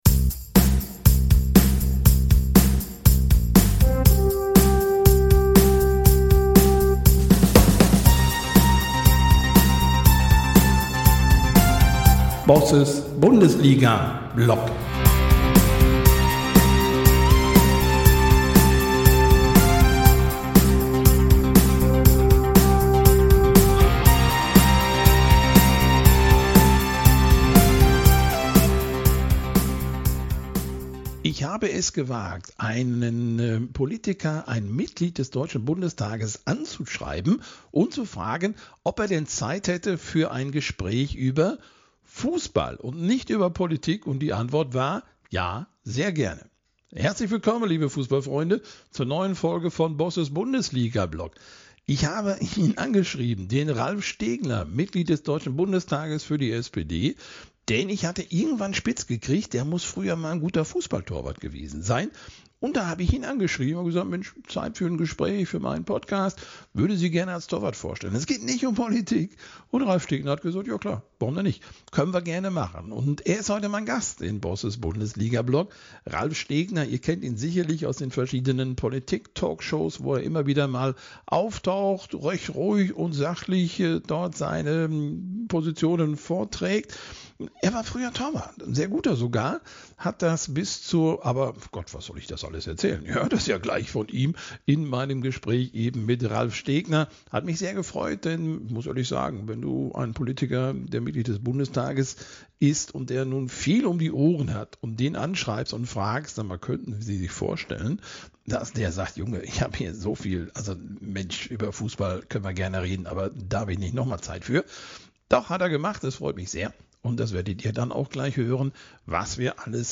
Beschreibung vor 2 Monaten In dieser Folge spreche ich mit Ralf Stegner, SPD-Bundestagsabgeordneter und früherer Fußball-Torwart. Wir reden darüber, wie ihn der Sport geprägt hat, welche Parallelen es zwischen dem Torwartspiel und der Politik gibt und welche Erfahrungen ihn bis heute leiten. Außerdem erzählt Ralf Stegner von seinen persönlichen Momenten zwischen Torpfosten und Parlament – von schnellen Entscheidungen, Teamgeist und Verantwortung – und gibt Einblicke in seinen Alltag als Politiker.